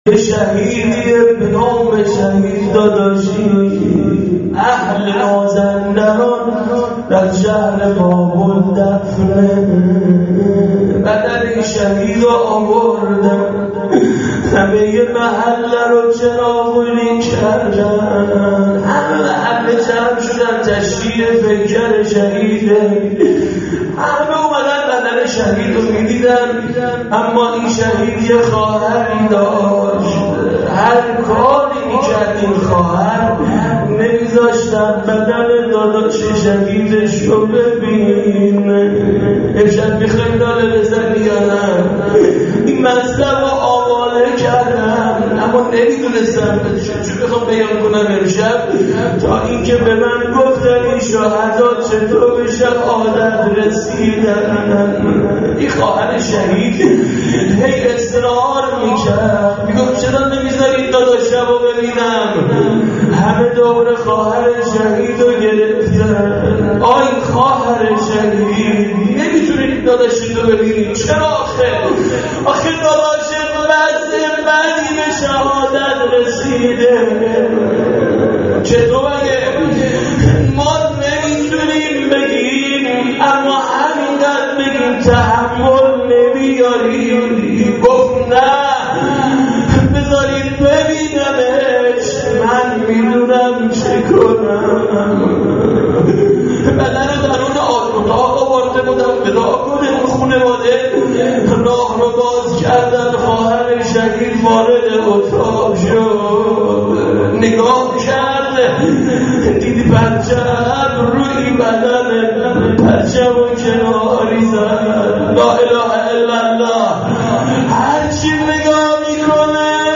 روضه شهدایی.MP3
روضه-شهدایی.mp3